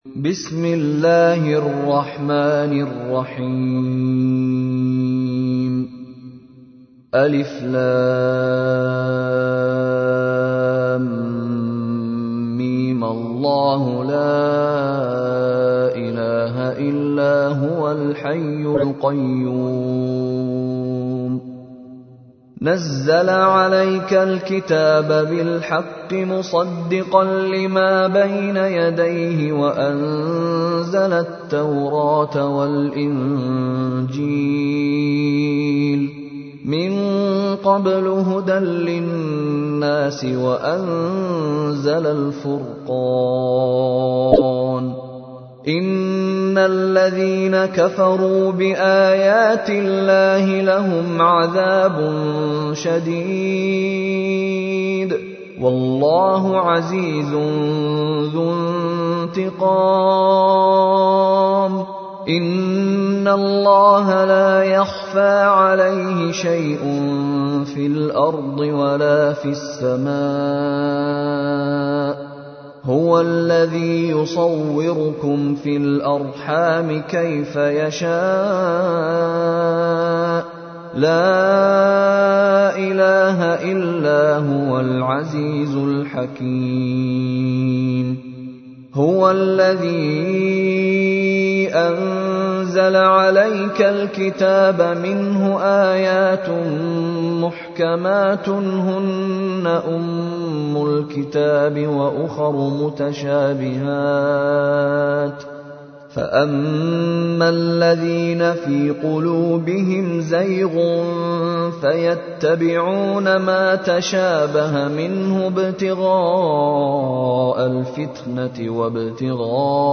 تحميل : 3. سورة آل عمران / القارئ مشاري راشد العفاسي / القرآن الكريم / موقع يا حسين